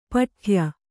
♪ paṭhya